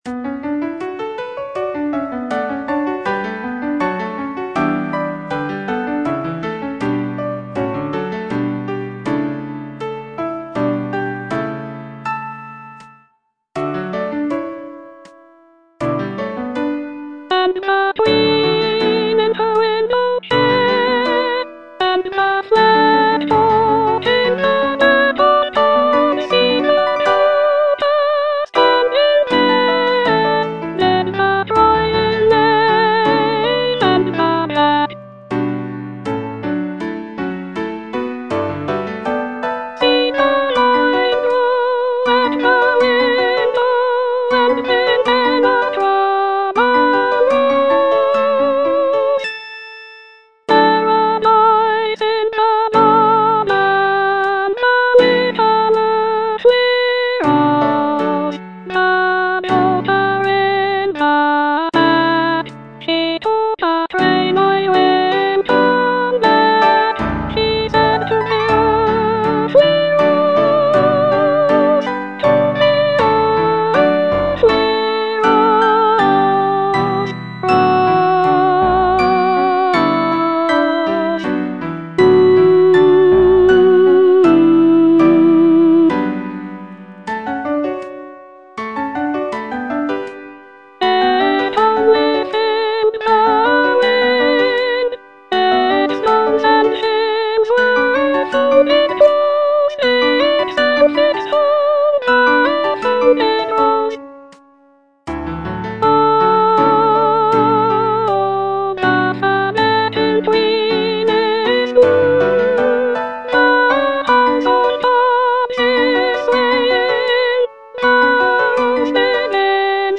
Soprano II (Voice with metronome)